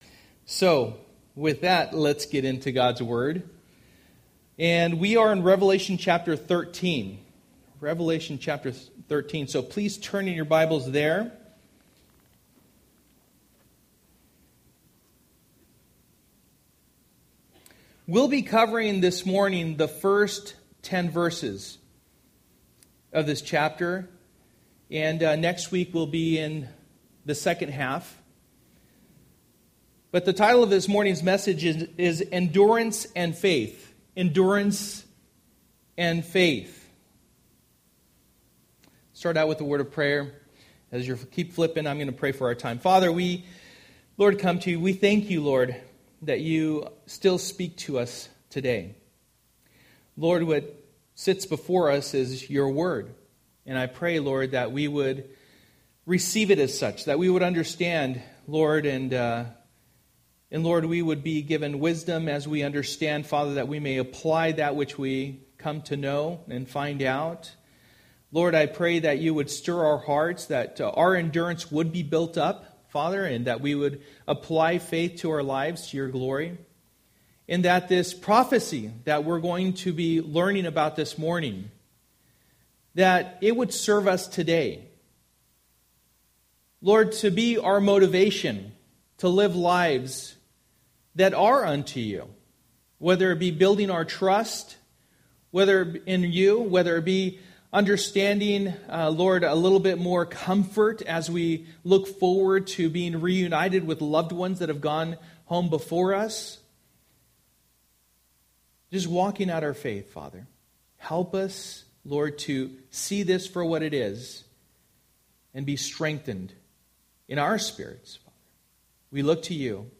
For the Time is Near Passage: Revelation 13:1-10 Service: Sunday Morning